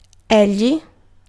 Ääntäminen
IPA : /hi/